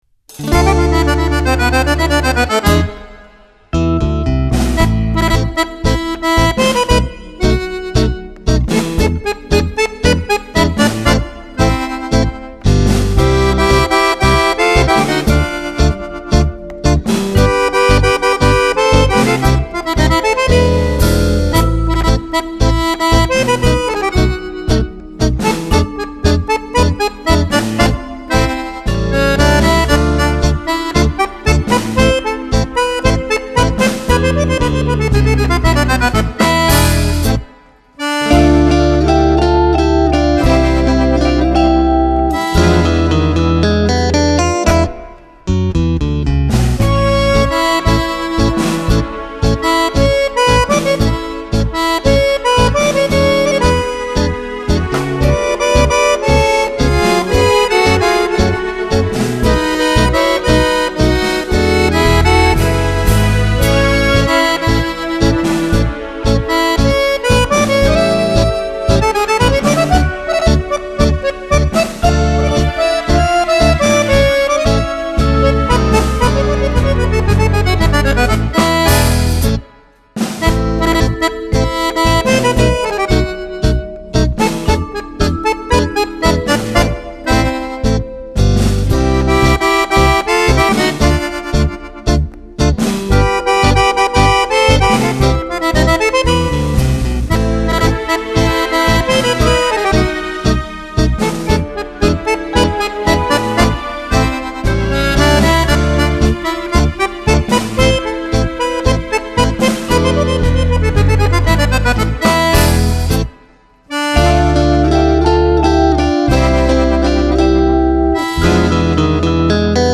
Genere: Tango